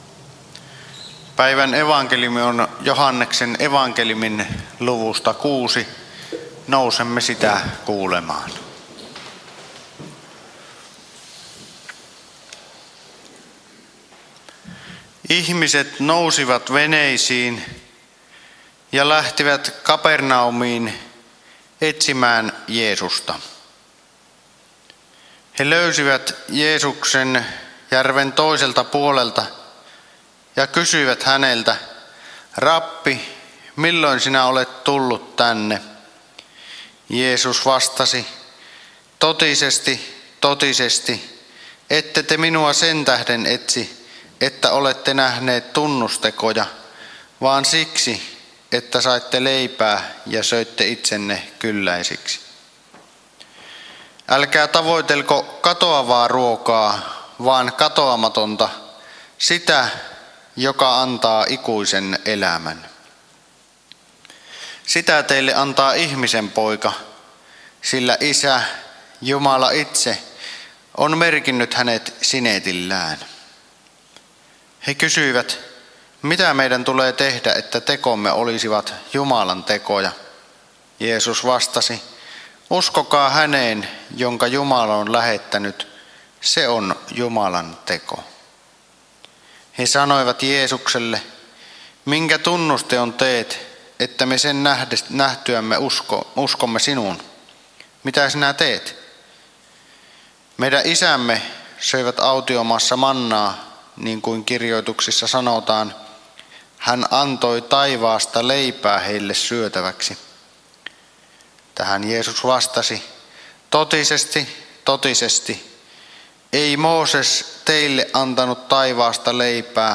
saarna Kälviällä 4. paastonajan sunnuntaina Tekstinä Joh. 6: 24-35